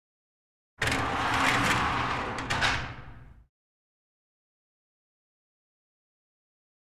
Metal Gate Roll / Slide Close 2, With Slight Reverb.